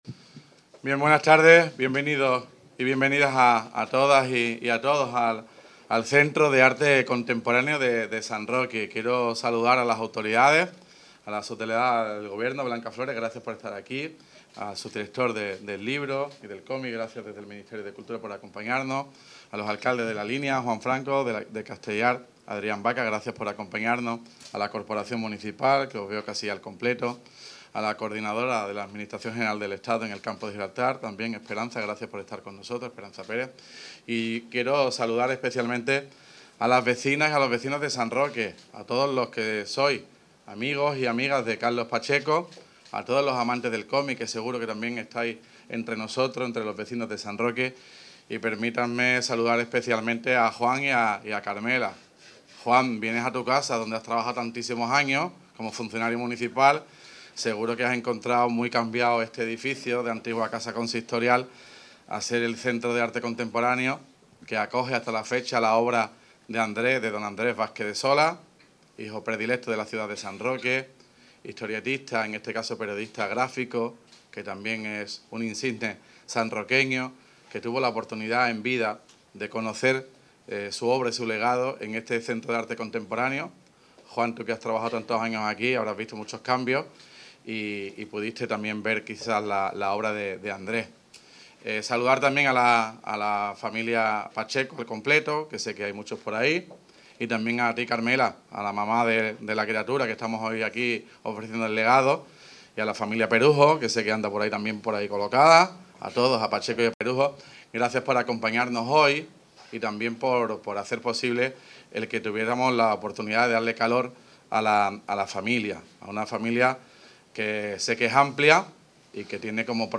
Por último, el alcalde de San Roque, Juan Carlos Ruiz Boix, dio la bienvenida a todos, autoridades, familia, amigos y ciudadanos, así como a la práctica totalidad de la Corporación municipal.
INAUGURACION CENTRO CARLOS PACHECO (TOTAL ALCALDE 1).mp3